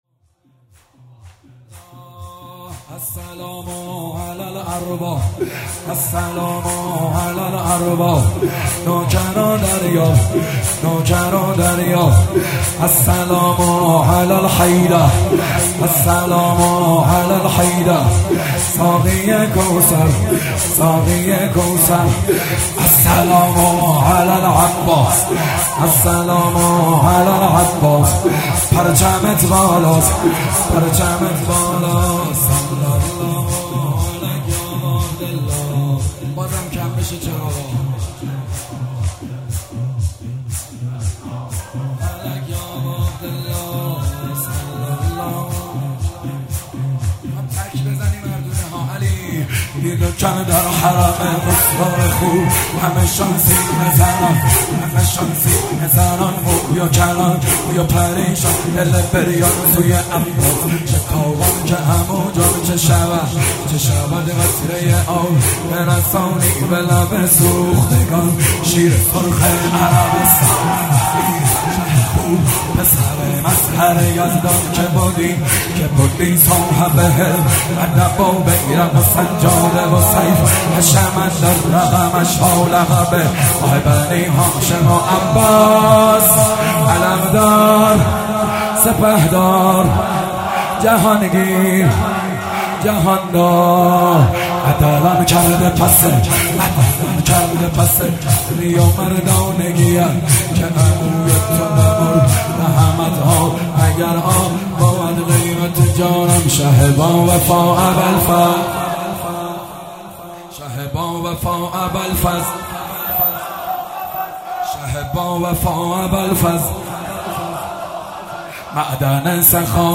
شور جدید